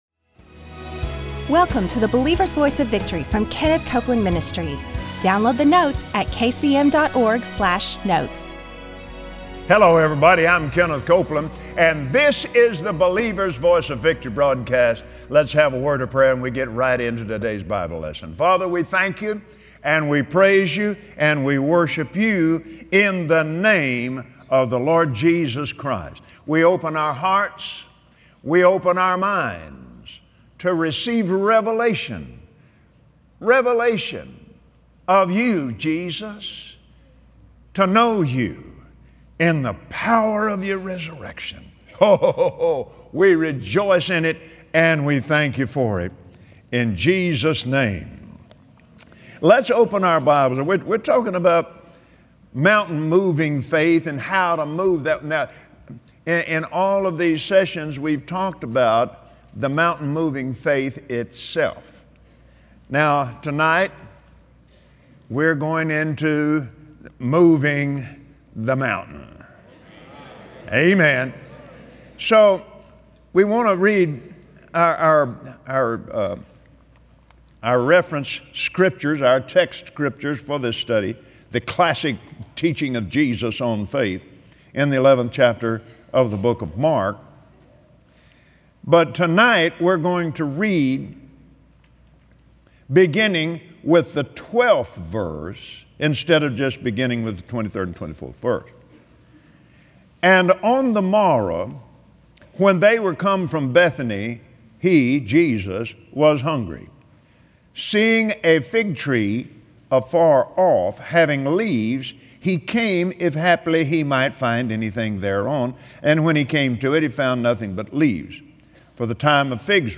Today, on the Believer’s Voice of Victory, learn from Kenneth Copeland how to grow your faith. Begin doing the things that build and nourish your spirit and walk in His promises.